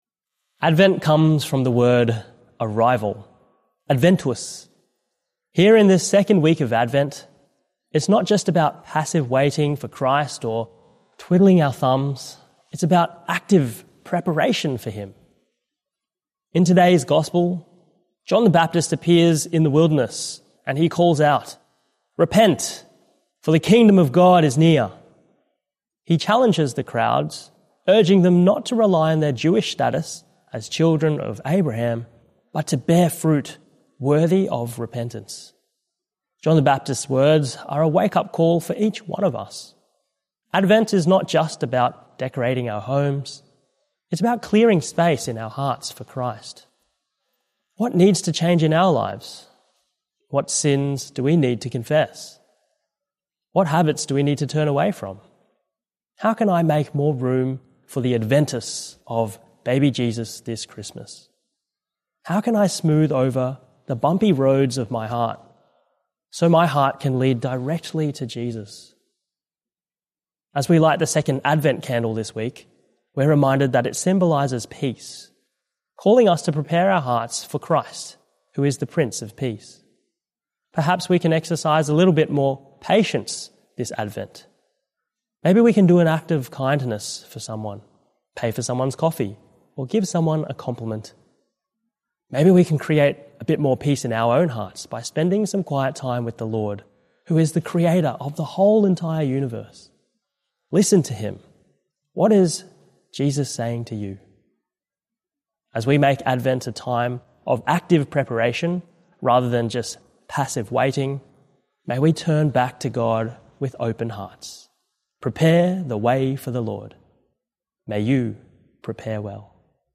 Archdiocese of Brisbane Second Sunday of Advent - Two-Minute Homily